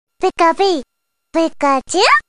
Play, download and share Pikachu 10 original sound button!!!!